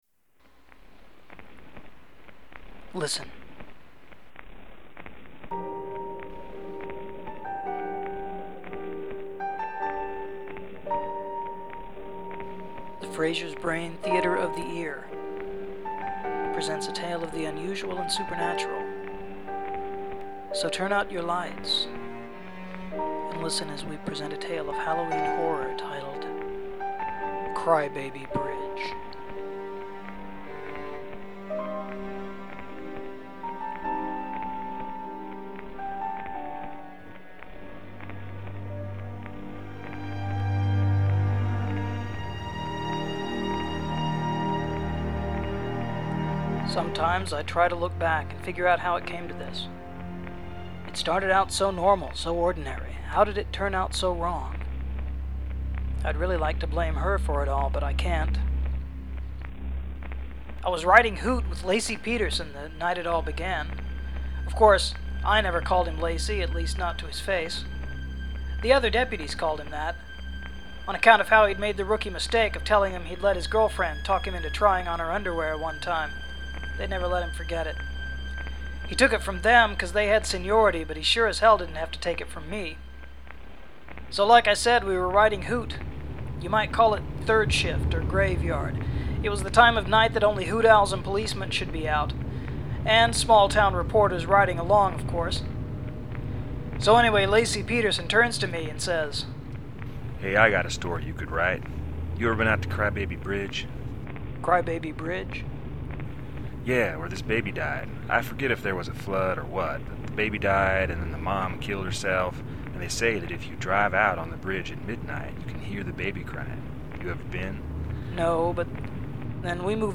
There’s nothing new here, because I’ve been working like a dog on a special audio presentation over at They Stole Frazier’s Brain.